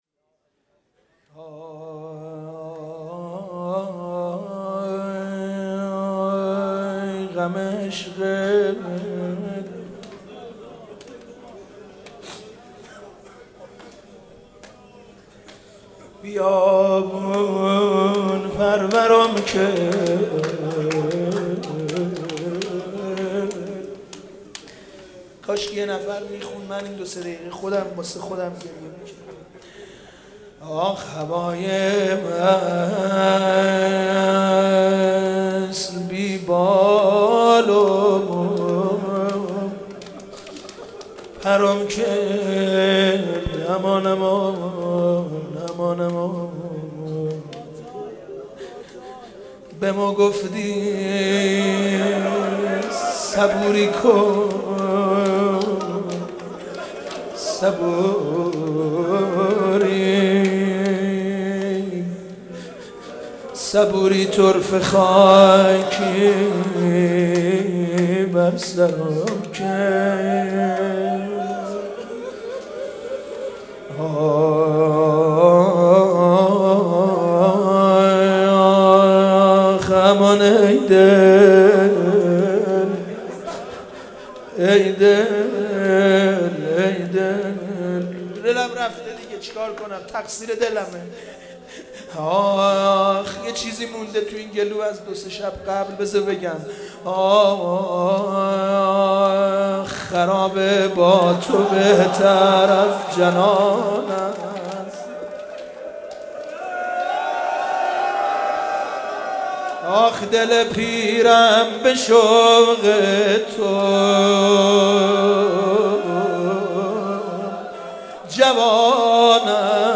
مراسم عزاداری شب پنجم محرم ۹۴
مداحی
در هیات رزمندگان قم برگزار شد.